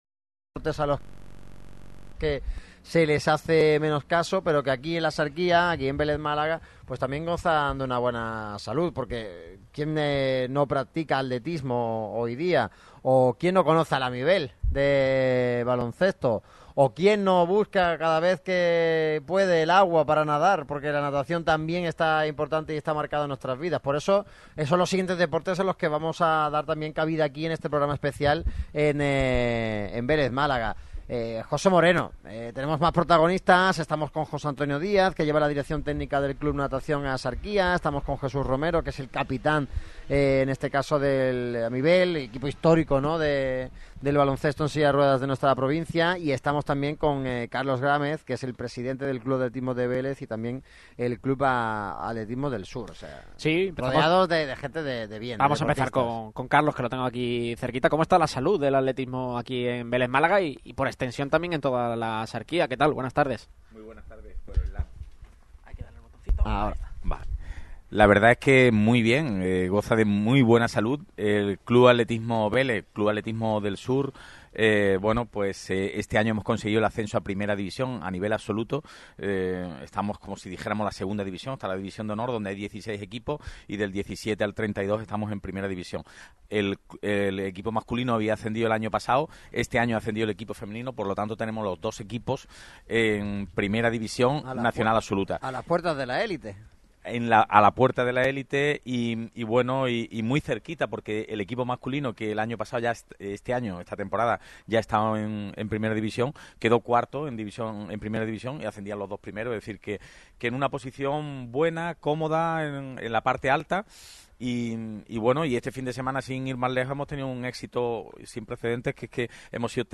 Durante el programa especial de Radio MARCA Málaga de hoy realizado en el Estadio Vivar Téllez hemos contado con la presencia de representantes de diferentes deportes que representan a La Axarquía a nivel nacional e internacional.